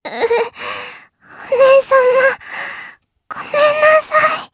Lilia-Vox_Dead_jp.wav